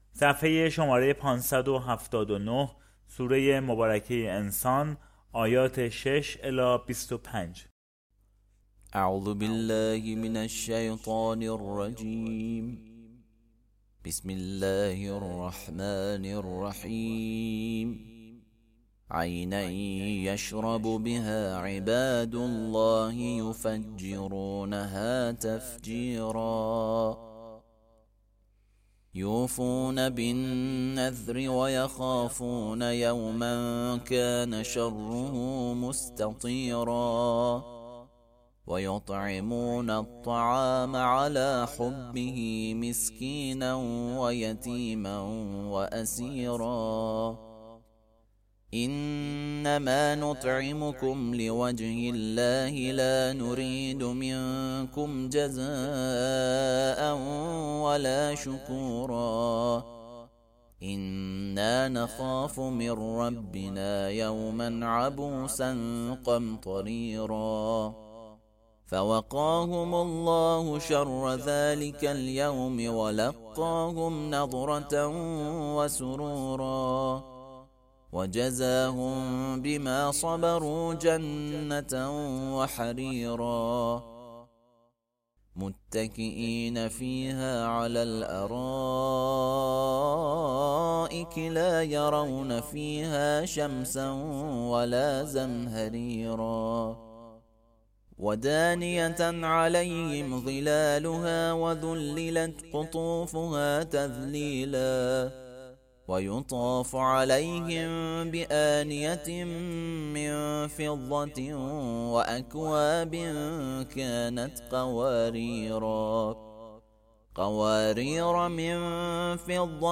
ترتیل صفحه ۵۷۹ از سوره انسان (جزء بیست و نهم)
ترتیل سوره(انسان)